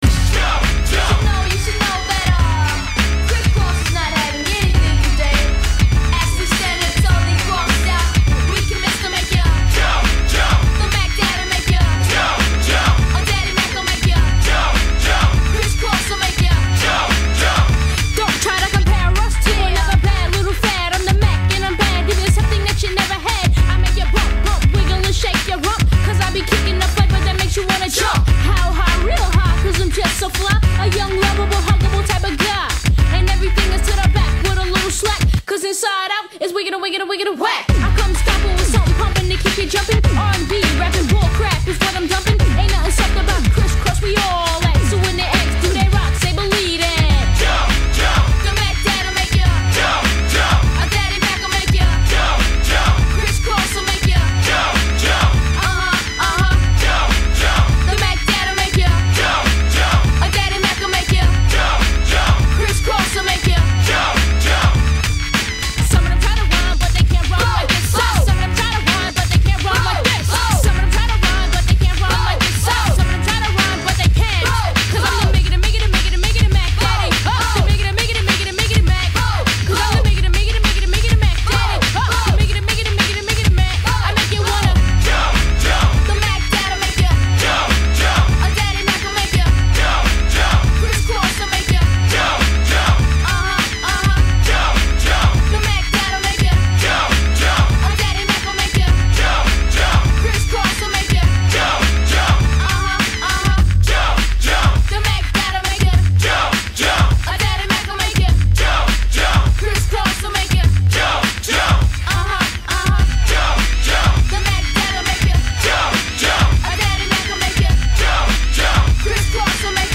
BPM102-102
Audio QualityPerfect (High Quality)
BPM 102 • 2'14" • Hip Hop • -/8/12/16